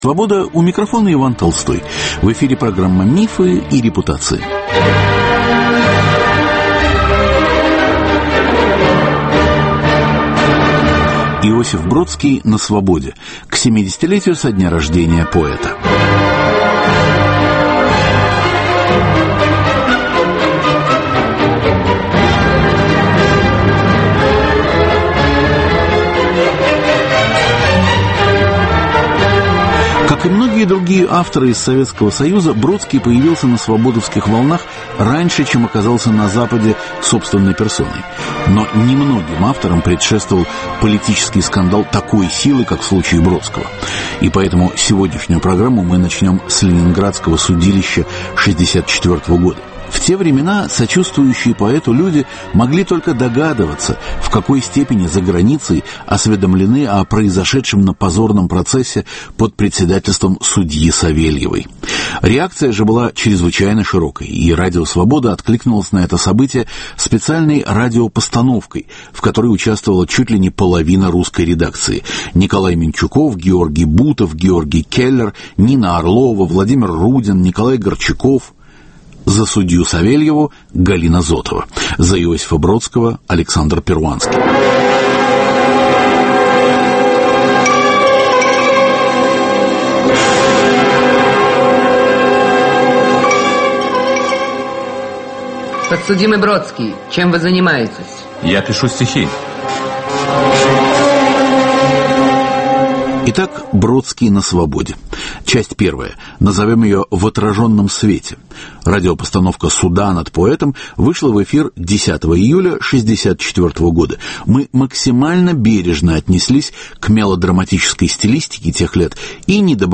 Иосиф Бродский на Свободе: к 70-летию со дня рождения поэта. Мы представляем архивные записи разных лет - от радиоинсценировки суда над Бродским 1964 года и самого первого интервью Свободе (77-го года) - до размышлений о русской, английской и американской поэзии, о преподавании, о читателях и переводимости литературы.